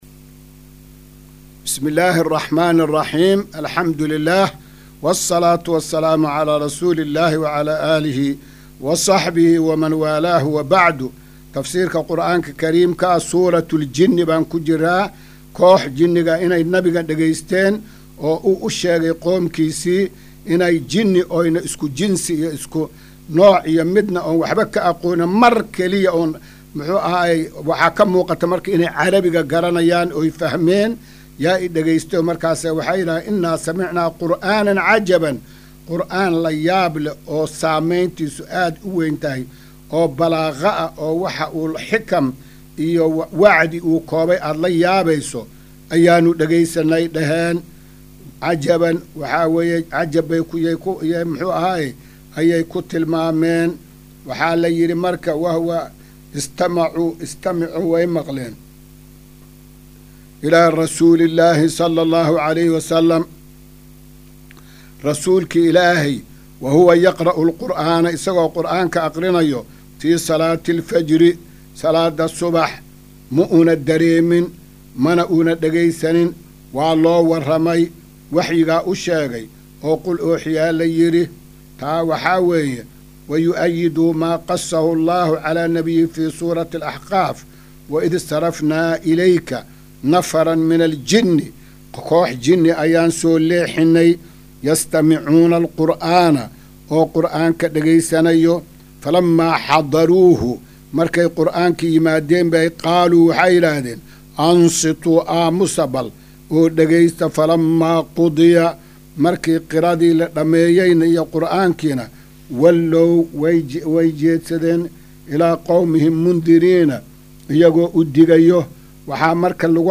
Maqal:- Casharka Tafsiirka Qur’aanka Idaacadda Himilo “Darsiga 274aad”